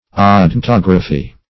Odontography \O`don*tog"ra*phy\, n. A description of the teeth.